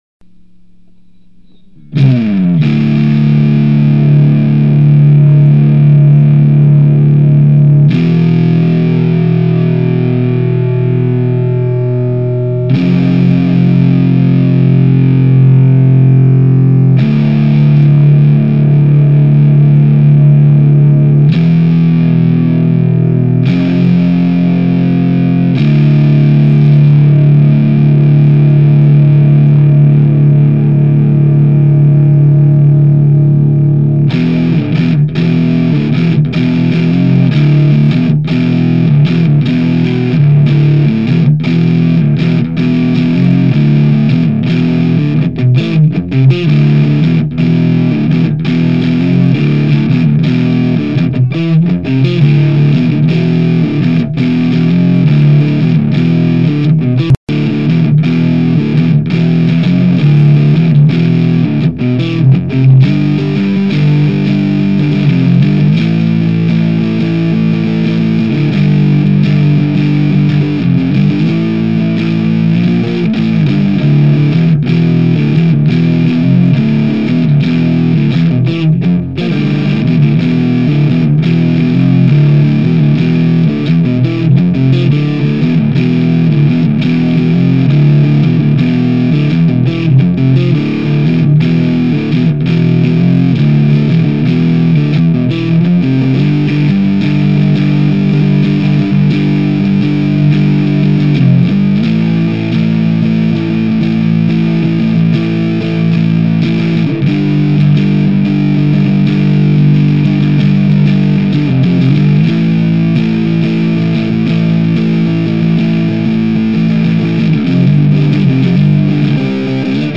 Below are just a few riffs that I recorded so I won't forget.
- Recorded through my mic'ed  Marshall Half Stack.